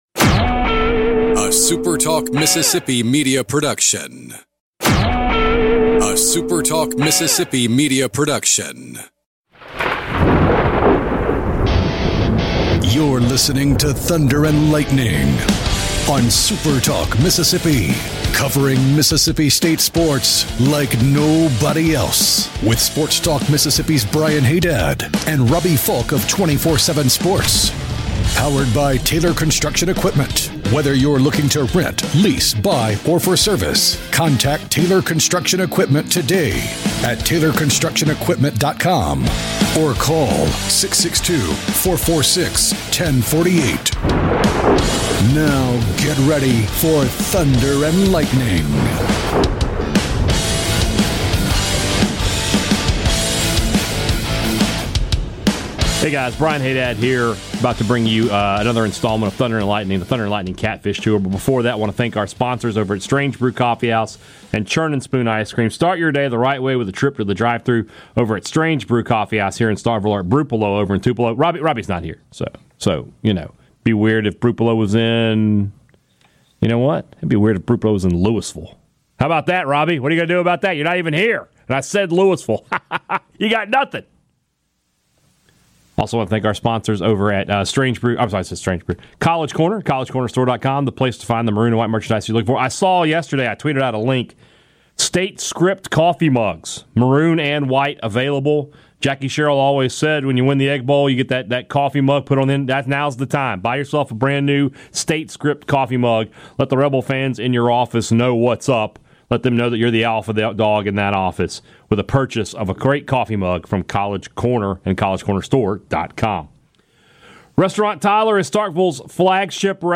Best of T&L: The T&L Catfish Tour – Clarkson’s General Store 52 minutes Posted Dec 28, 2023 at 12:53 pm . 0:00 52:32 Add to My Queue Download MP3 Share episode Share at current time Show notes Thunder & Lightning is back on the road for stop number three on the Catfish Tour presented by Superior Catfish.